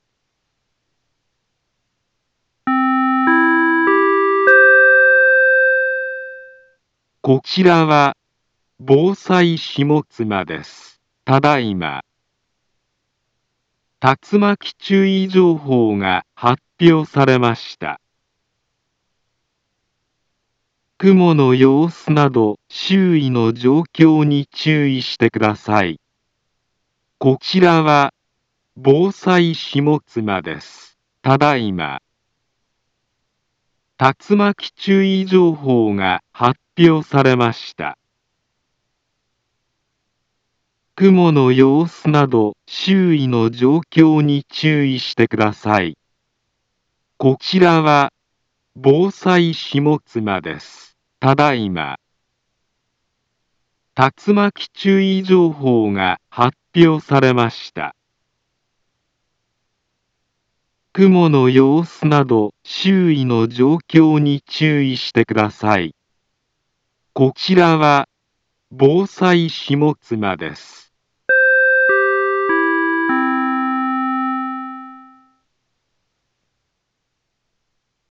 Back Home Ｊアラート情報 音声放送 再生 災害情報 カテゴリ：J-ALERT 登録日時：2023-09-08 17:55:07 インフォメーション：茨城県北部、南部は、竜巻などの激しい突風が発生しやすい気象状況になっています。